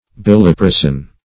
Search Result for " biliprasin" : The Collaborative International Dictionary of English v.0.48: Biliprasin \Bil`i*pra"sin\, n. [L. bilis bile + prasinus green.]